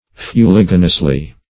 fuliginously - definition of fuliginously - synonyms, pronunciation, spelling from Free Dictionary Search Result for " fuliginously" : The Collaborative International Dictionary of English v.0.48: Fuliginously \Fu*lig"i*nous*ly\, adv.
fuliginously.mp3